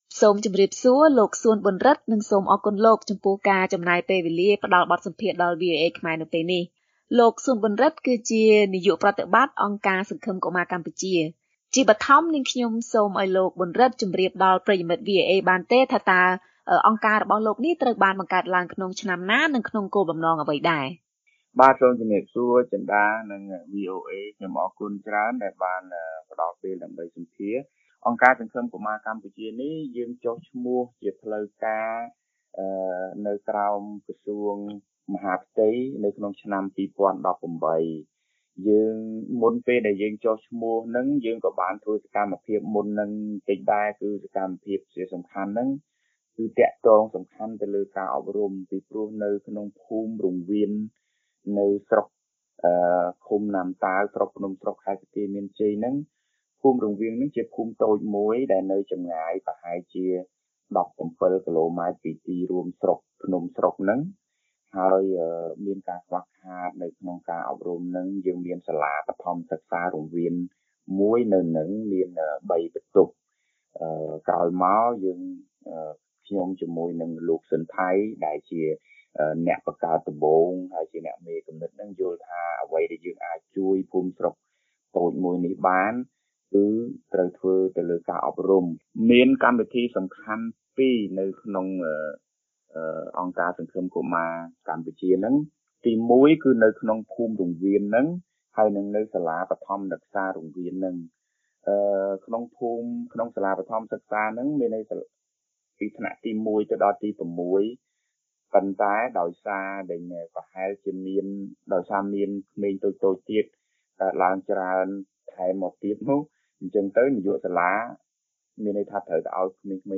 បទសម្ភាសន៍ VOA៖ អង្គការសង្ឃឹមកុមារកម្ពុជាមើលឃើញការអប់រំនៅជនបទជាគន្លឹះជួយឱ្យរួចផុតពីភាពក្រីក្រ